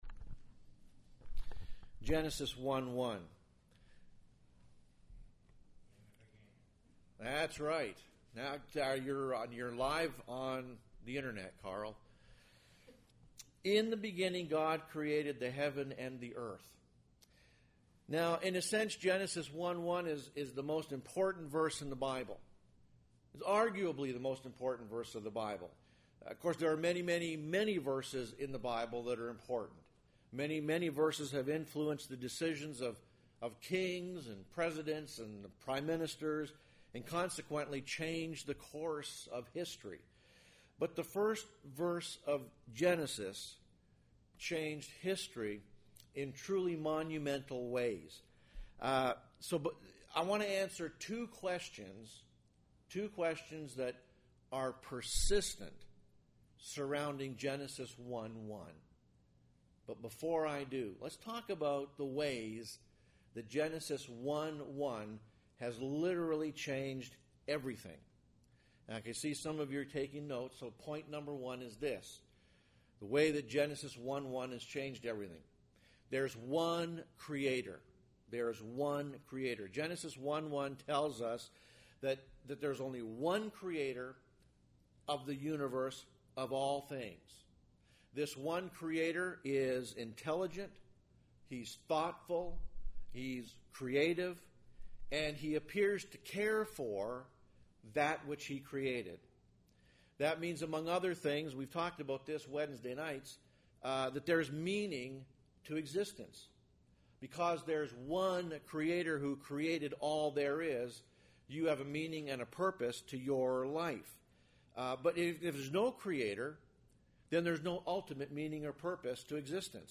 And for those of you who asked, here is yesterday’s sermon, the audio version.